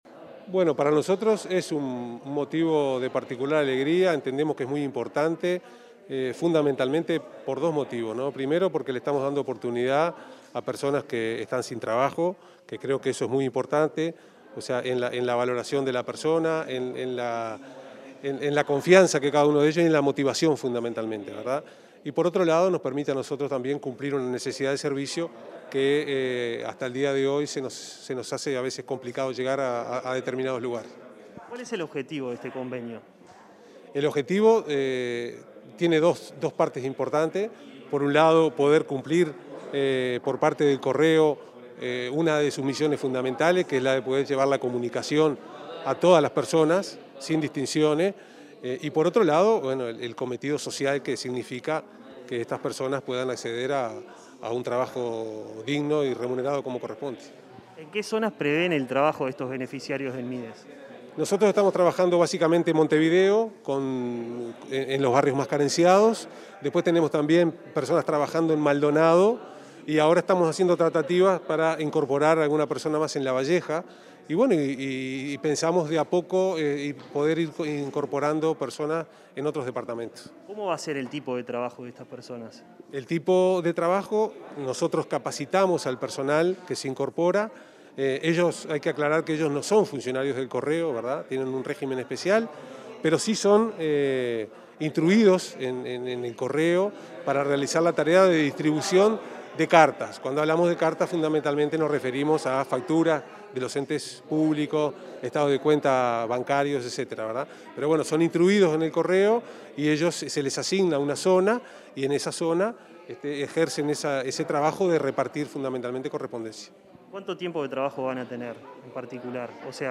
Entrevista al presidente del Correo, Rafael Navarrine
El titular del Correo, Rafael Navarrine, dialogó con Comunicación Presidencial acerca del acuerdo suscripto este martes 26 con el ministro de